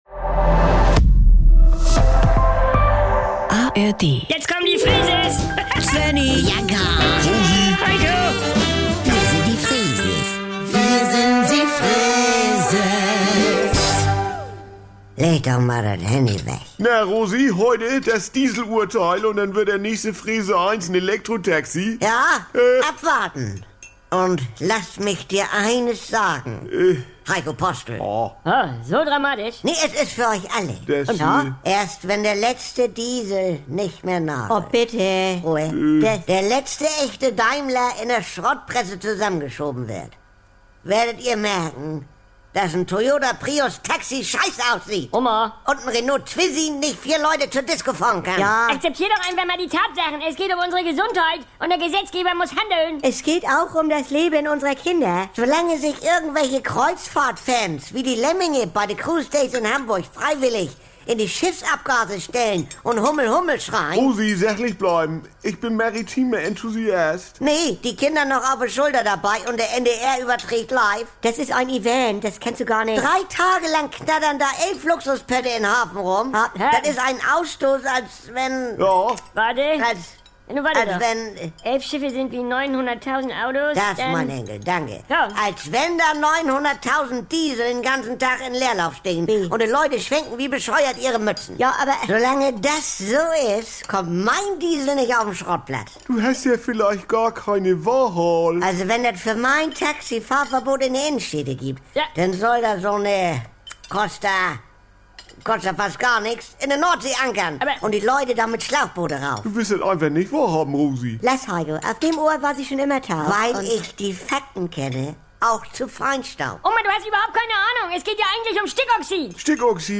Saubere Komödien Unterhaltung NDR 2 Komödie